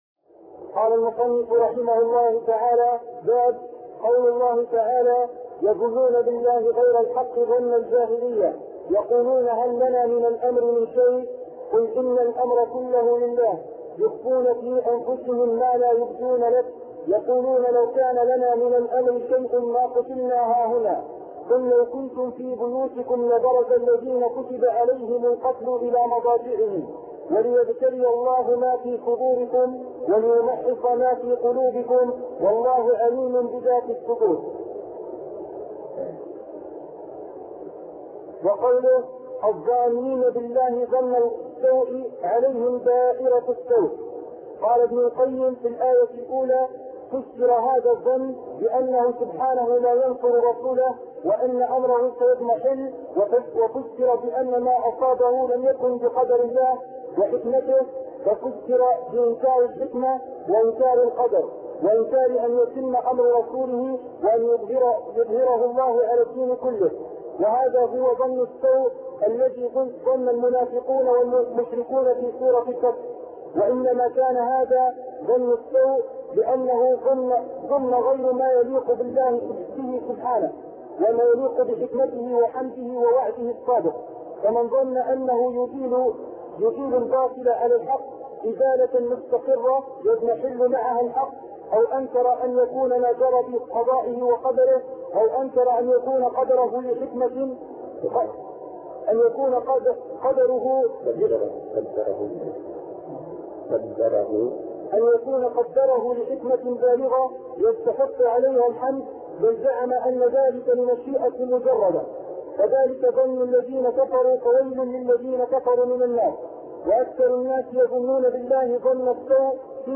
عنوان المادة الدرس ( 124) شرح فتح المجيد شرح كتاب التوحيد تاريخ التحميل الجمعة 16 ديسمبر 2022 مـ حجم المادة 23.12 ميجا بايت عدد الزيارات 223 زيارة عدد مرات الحفظ 139 مرة إستماع المادة حفظ المادة اضف تعليقك أرسل لصديق